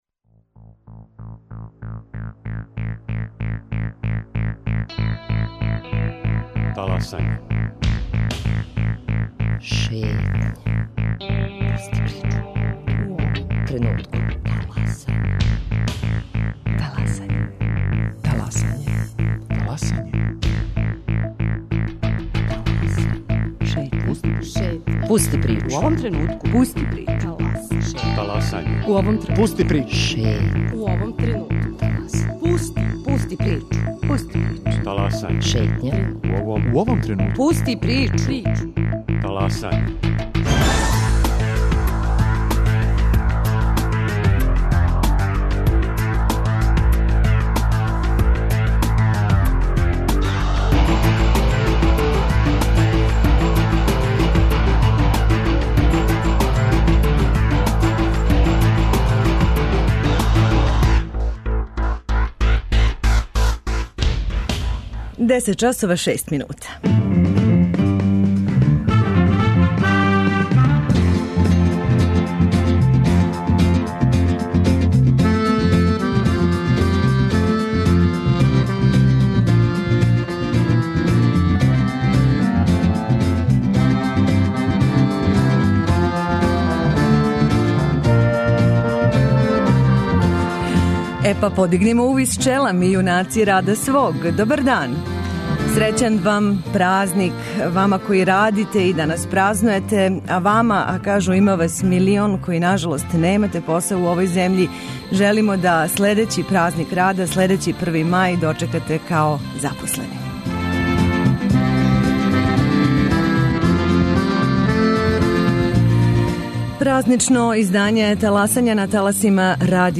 Добра музика, јављање репортера из градова широм Србије са најпознатијих и оних мање познатих излетишта!